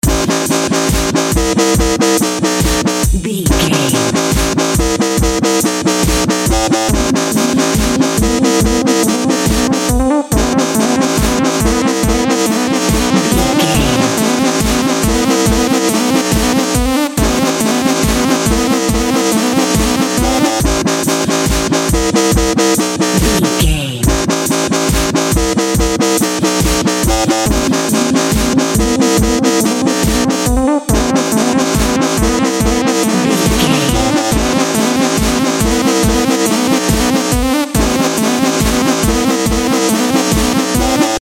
Forceful Dubstep.
Epic / Action
Fast paced
Aeolian/Minor
G#
aggressive
powerful
dark
driving
energetic
drum machine
synthesiser
electronic
synth lead
synth bass